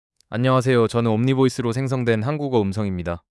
01_auto_ko — auto 모드, 한국어
영어(00_auto_en)는 꽤 자연스러운데, 한국어(01_auto_ko)는 톤이 많이 평평하고 억양이 어색한 느낌이 있어요. voice design 쪽은 female_british, male_low, whisper 모두 지정한 캐릭터가 어느 정도 구분돼 들리긴 해요.
AI, TTS